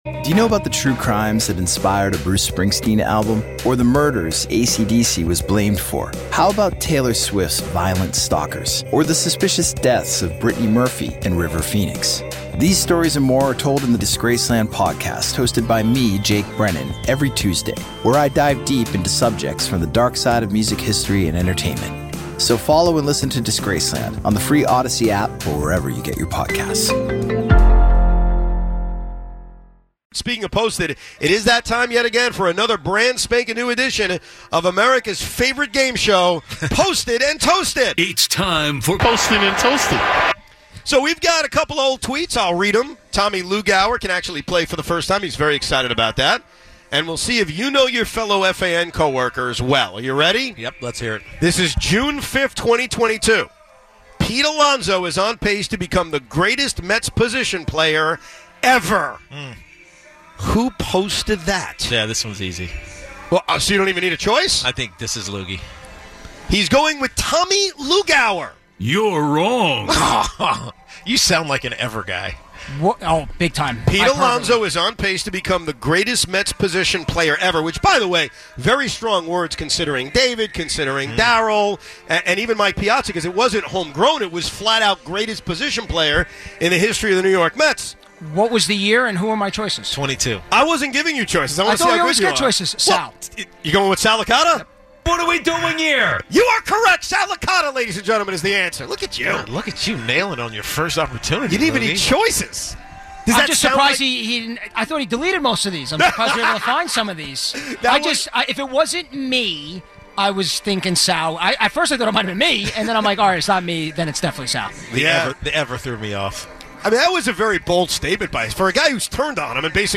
at Barclays ahead of Knicks-Nets